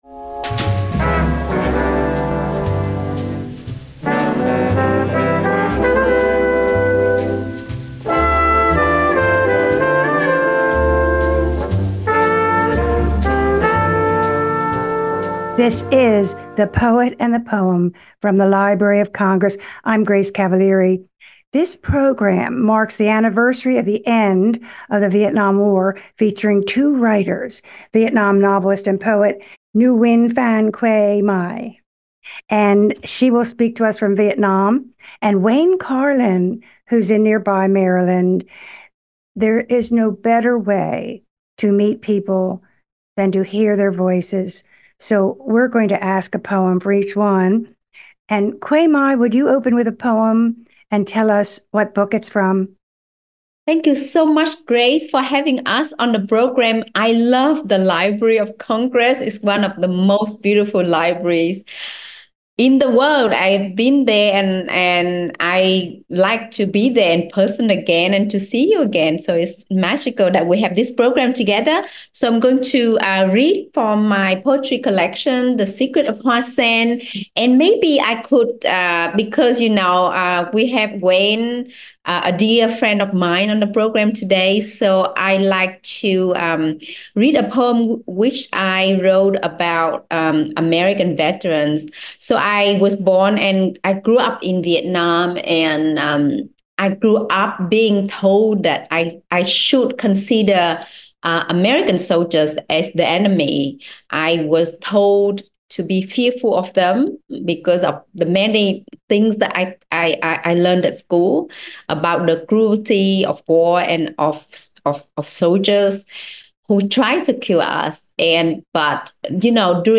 Interviews with U.S. Poets Laureate